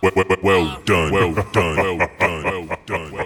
kits/Southside/Vox/Well Done.wav at 32ed3054e8f0d31248a29e788f53465e3ccbe498